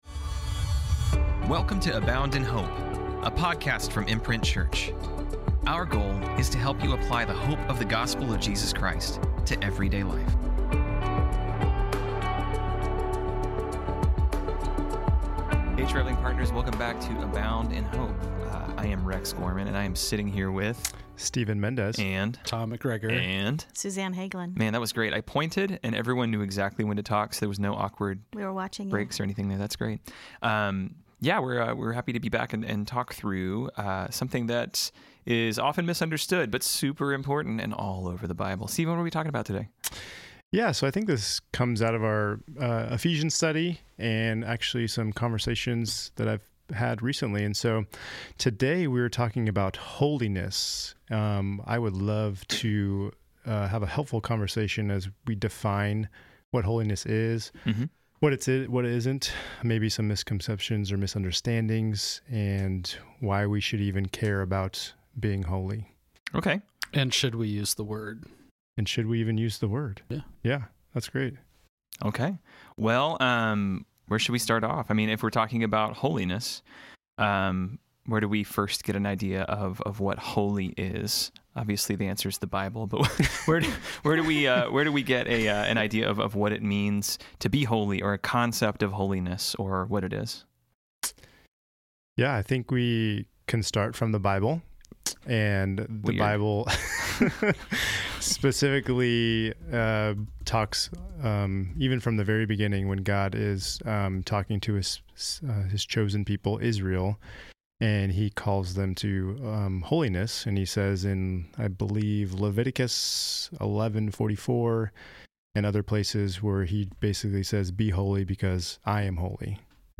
The conversation includes drawn out definitions, heady discussions, and misquoting C.S. Lewis.